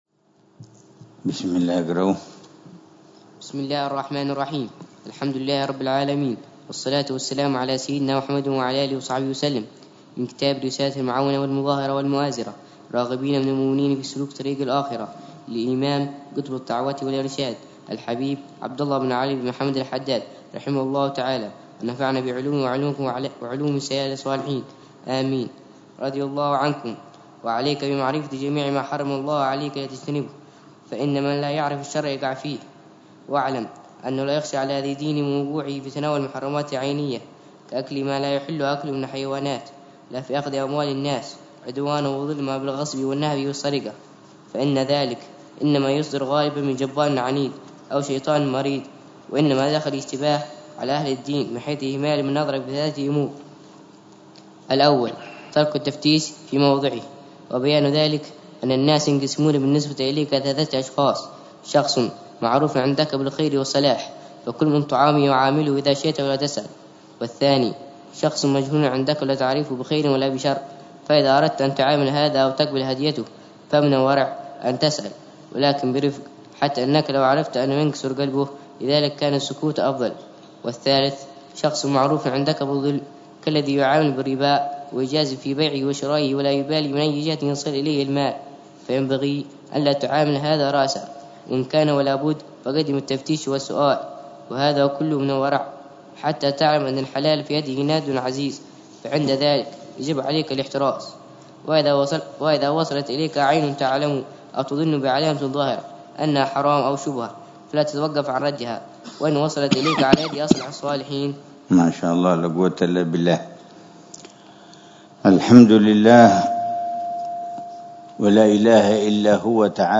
قراءة بتأمل وشرح واقعي لكتاب رسالة المعاونة للإمام عبد الله بن علوي الحداد، يلقيها الحبيب عمر بن محمد بن حفيظ لكبار طلاب حلقات المساجد